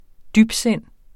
Udtale [ ˈdybˌsenˀ ]